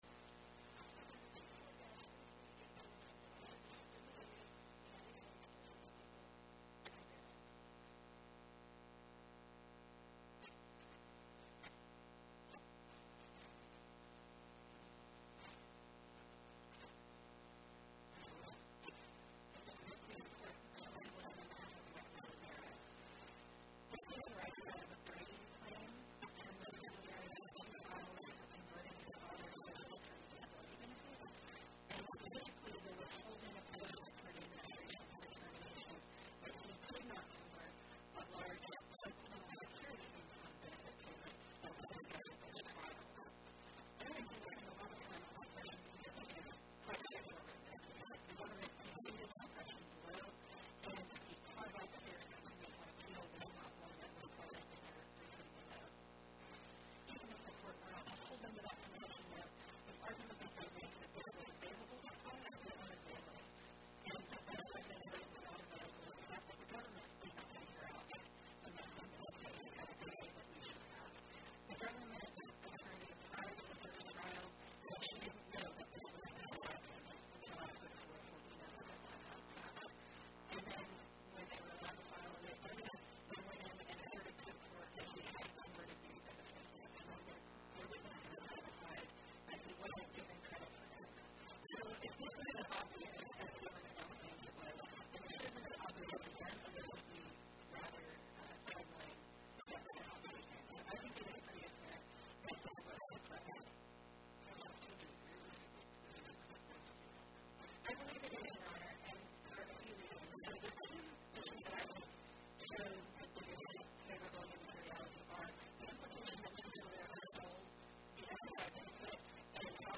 Background